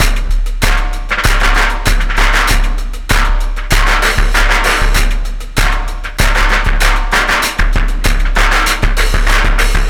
Bloody Sumday 097bpm